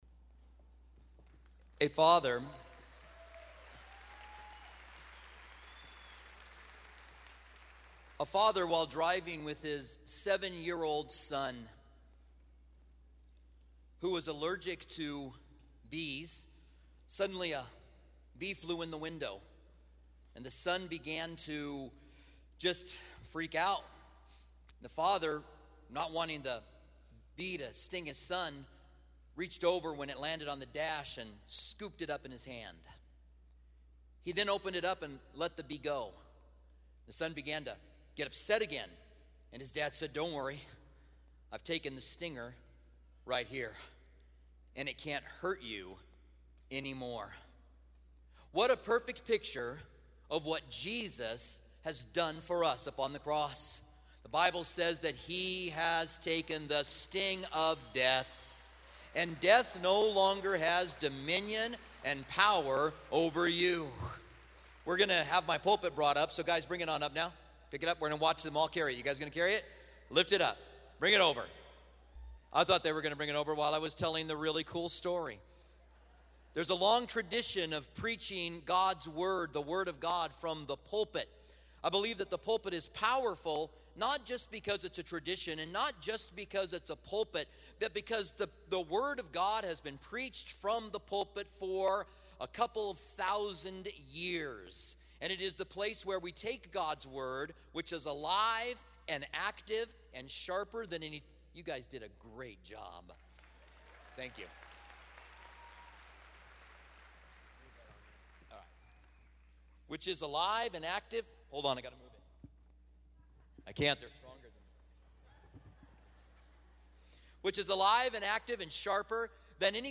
Easter Holiday Message